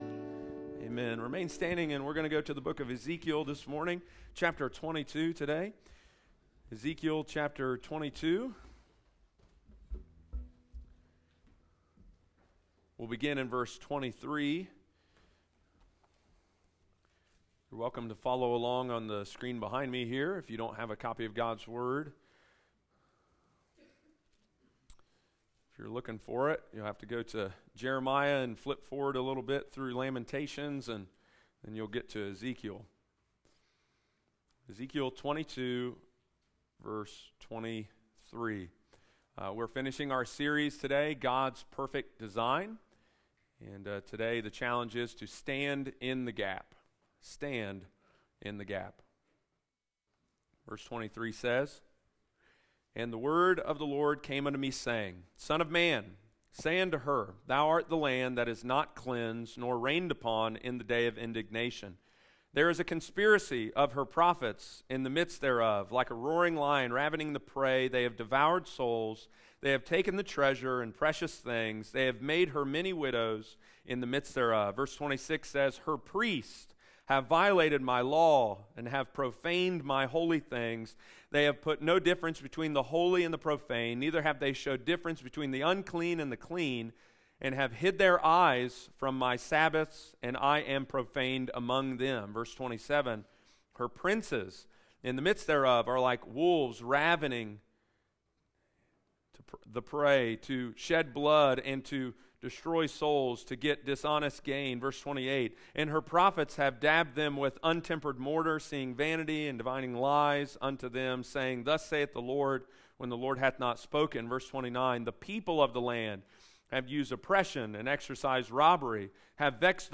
Join us for the final message of our mini-series, God’s Perfect Design!
Sunday morning, June 25, 2023.